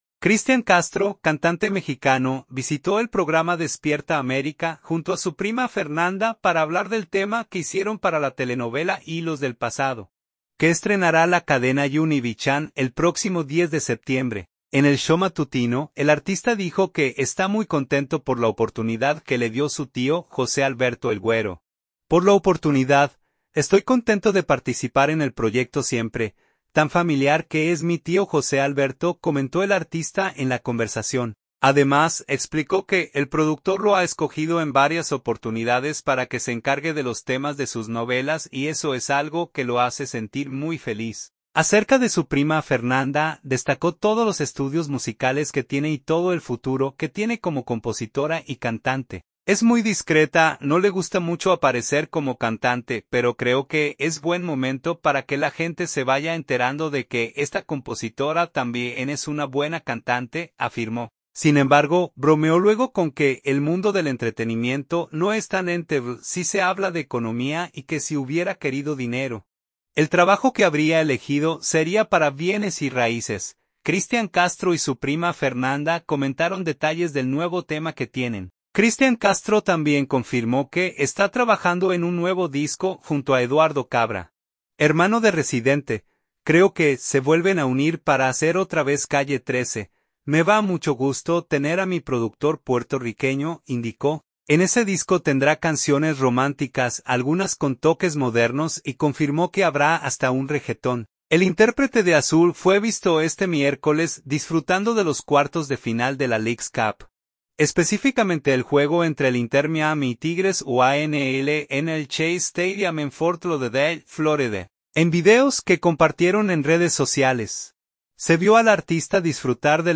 Cristian Castro visitó el show matutino de Univision, en donde contó cuáles son sus nuevos proyectos artísticos